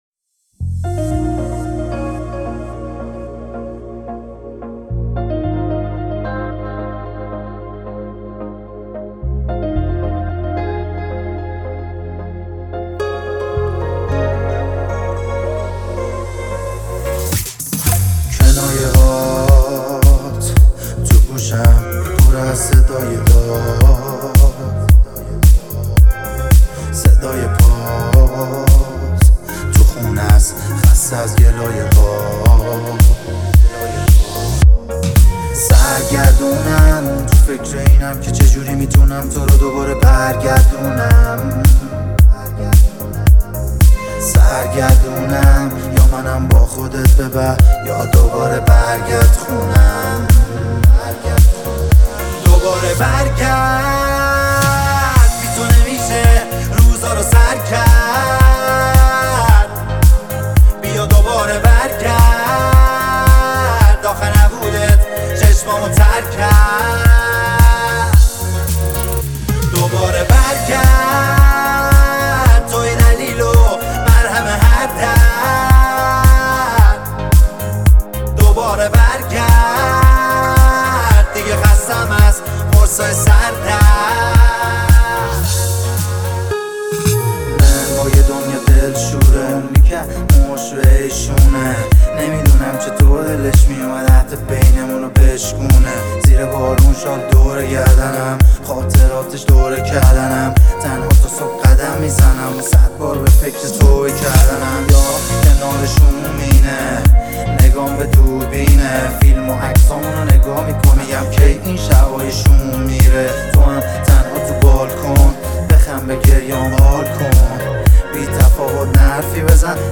احساسی